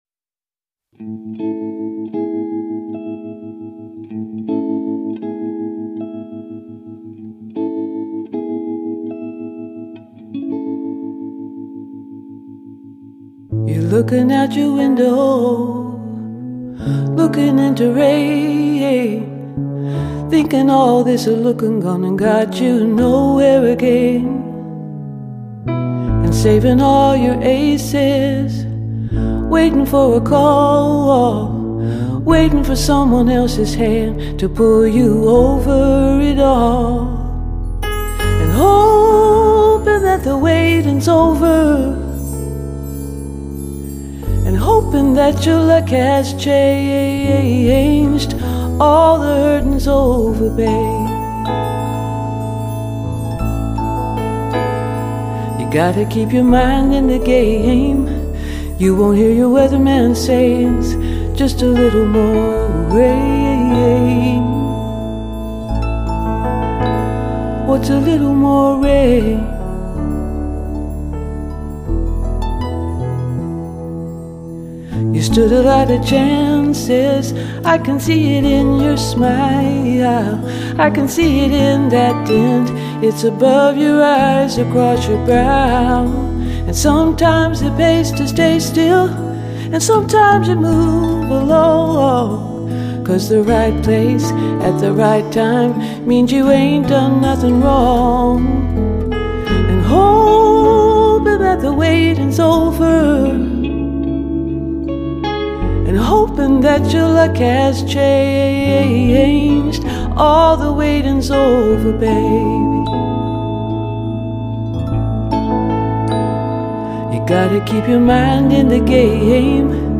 以超凡的音乐造诣配合她的老拍挡四弦木结他创作出12首
她以温醇磁性的声音，引人入胜的演译配合出
音师悉心制作，堪称最完美的sacd制式示范组合，人声与
吉他声更为玲珑剔透，为发烧乐迷带来又一试机必备之选